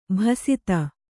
♪ bhasita